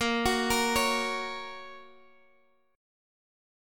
Bbm#5 chord